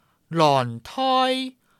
臺灣客語拼音學習網-進階學習課程-詔安腔-第九課